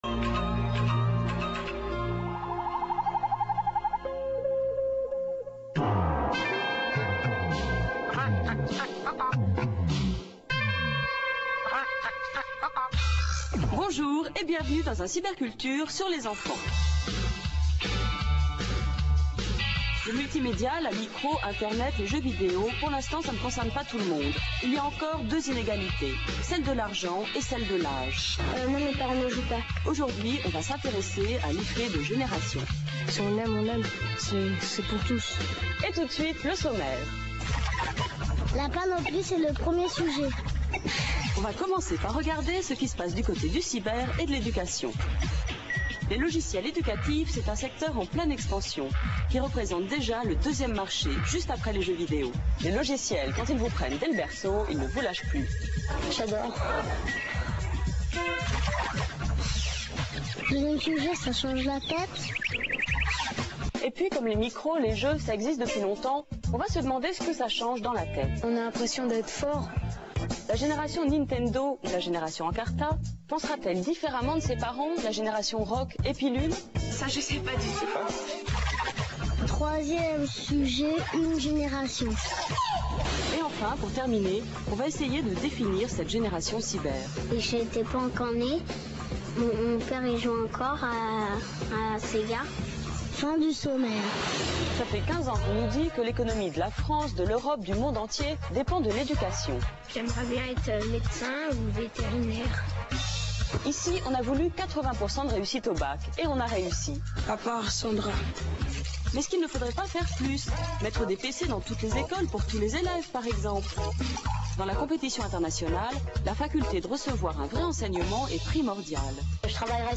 La qualité de la vidéo en streaming Real Multimedia est volontairement dégradée afin qu'elle soit rapidement téléchargée et affichée.